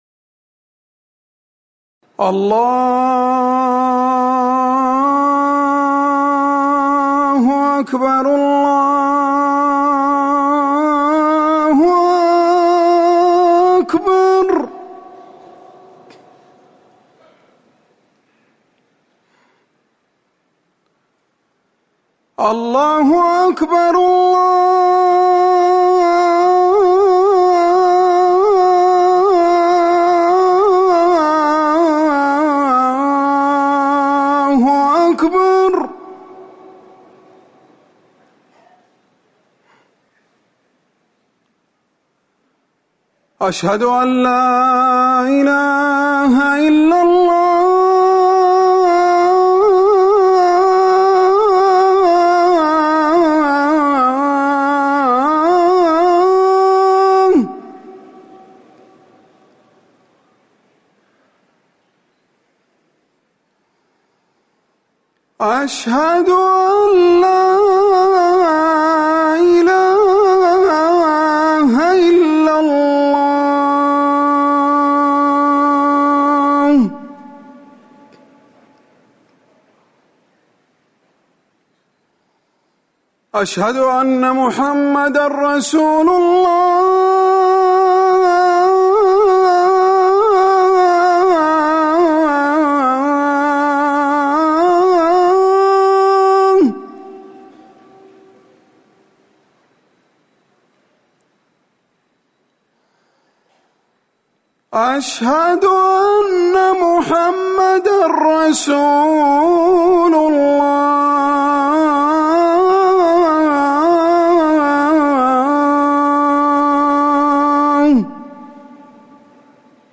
أذان العصر - الموقع الرسمي لرئاسة الشؤون الدينية بالمسجد النبوي والمسجد الحرام
المكان: المسجد النبوي